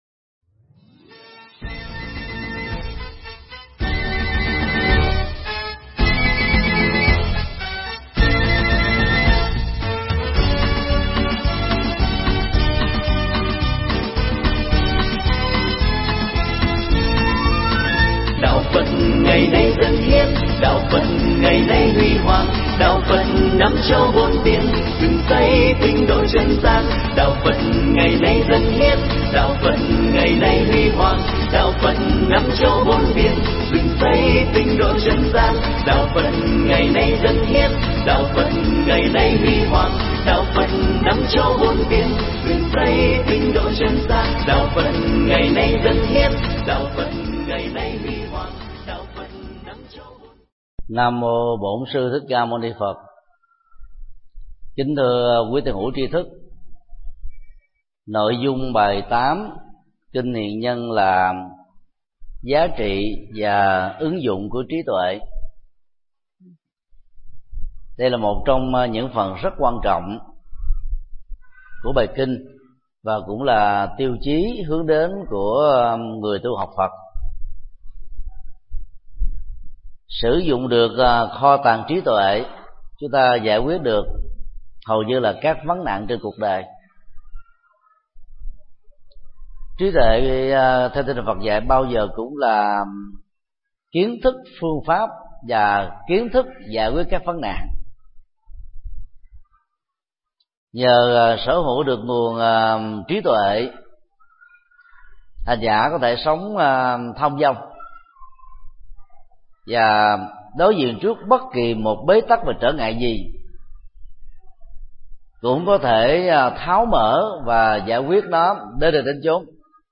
Mp3 Pháp Thoại Kinh Hiền Nhân 8
giảng tại chùa Xá Lợi